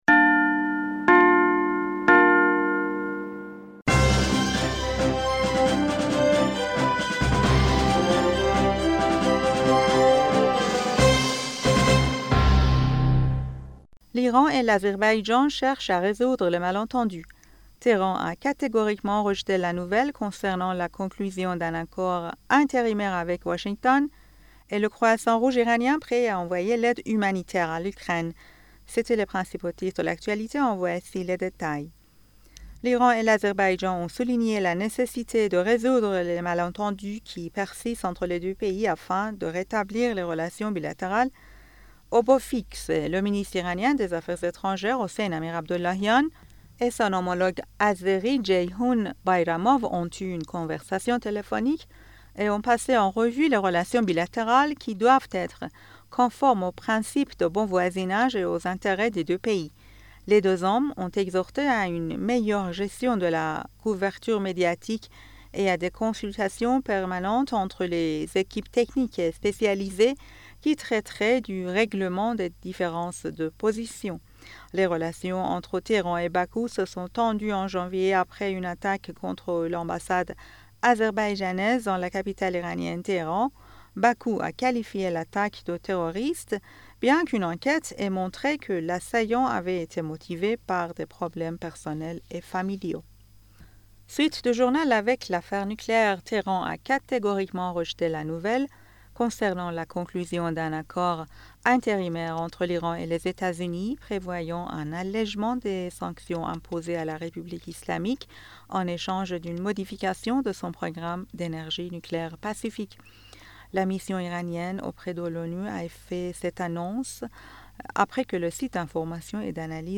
Bulletin d'information du 09 Juin 2023